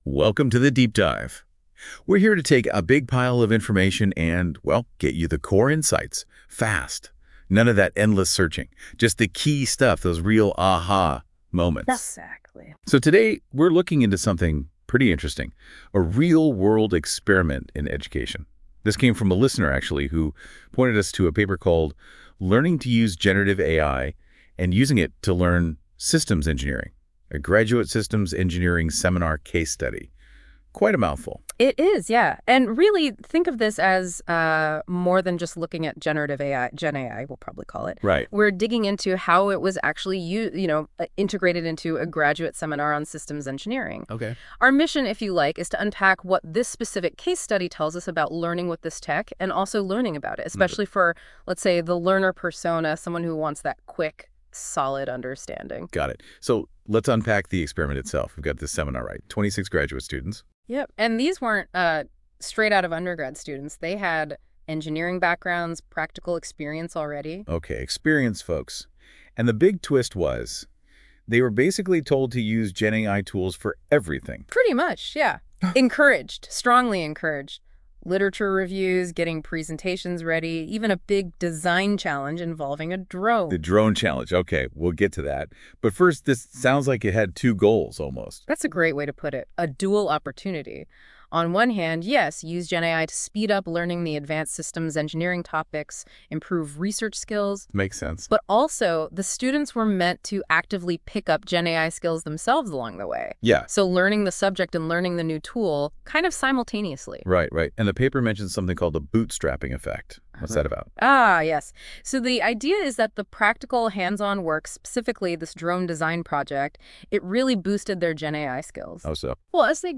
ניתן להאזין לפודקאסט המבוסס על המאמר, שנערך באמצעות הכלי NotebookLM.